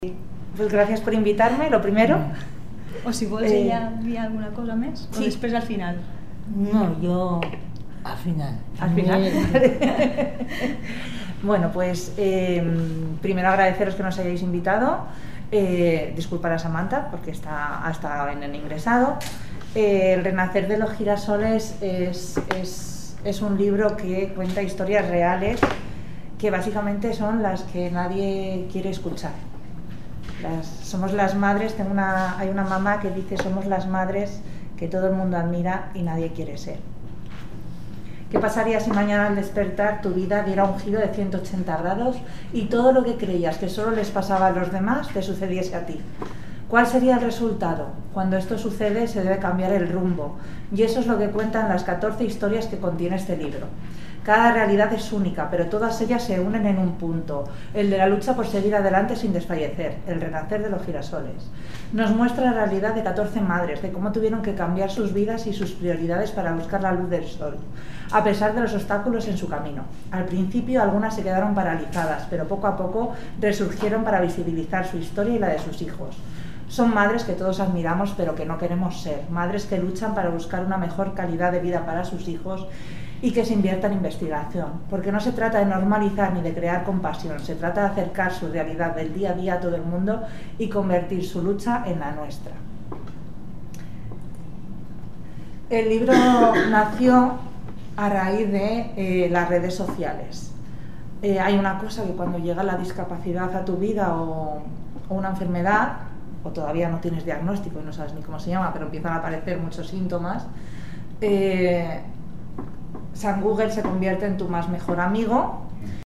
Con motivo del día internacional de la Diversidad Funcional que se conmemora hoy martes 3 de diciembre, la Asociación Iguals i Sense Través, ha llevado a cabo la presentación del libro El Renacer de los Girasoles.